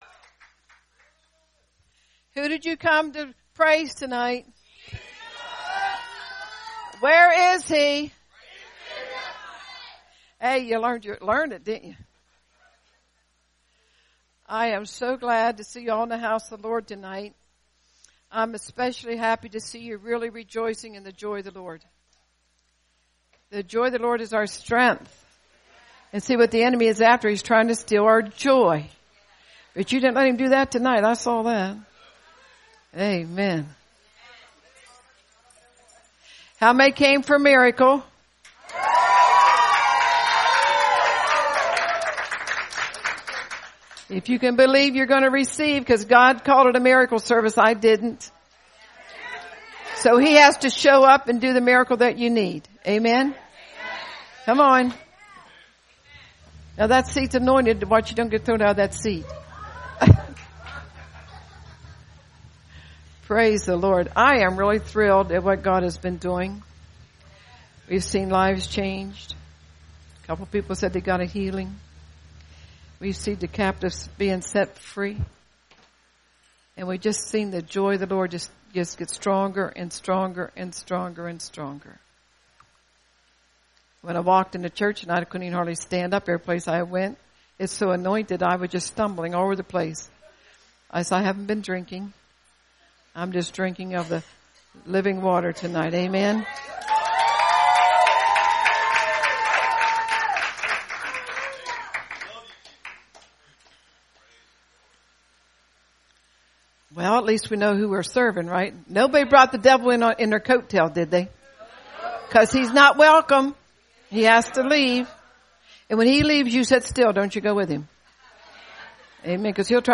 Great Miracle Service